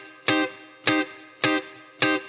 DD_TeleChop_105-Cmin.wav